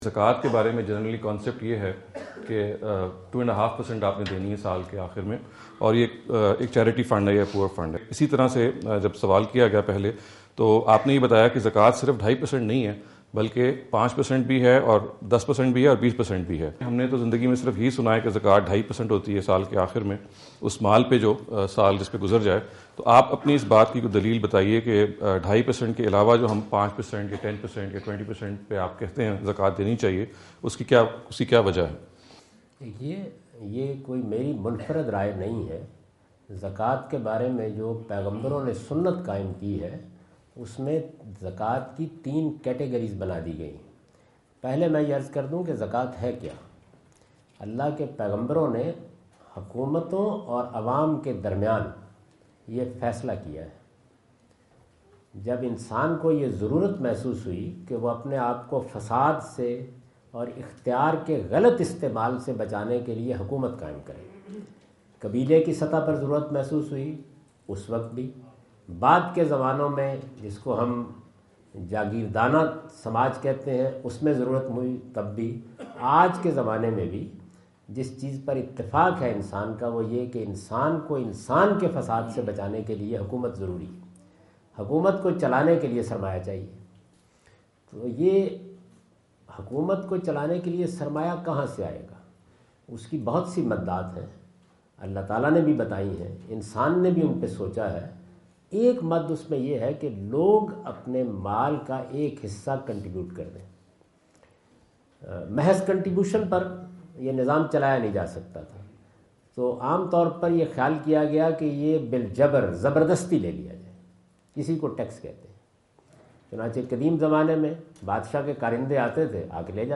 جاوید احمد غامدی اپنے دورہ امریکہ 2017 کے دوران کورونا (لاس اینجلس) میں "پیداوار اور بچت میں زکوٰۃ کی شرح" سے متعلق ایک سوال کا جواب دے رہے ہیں۔